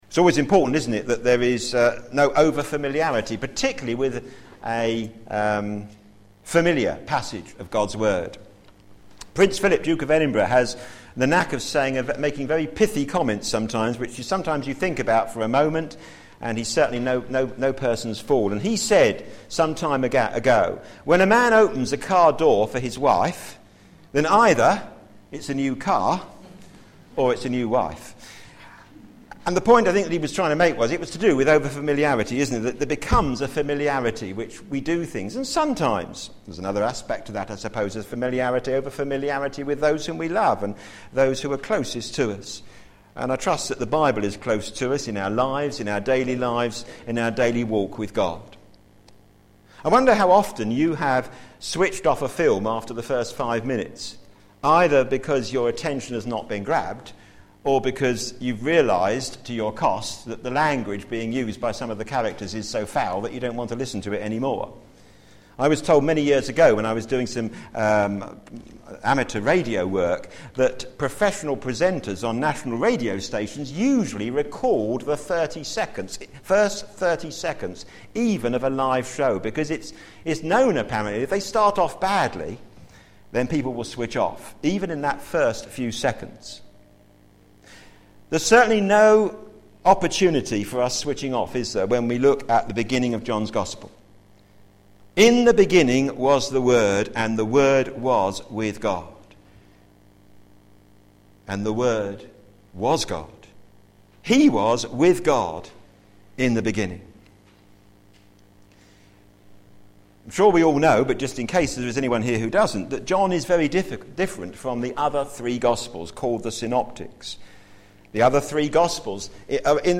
The Word Became Flesh Sermon